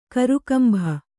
♪ karukambha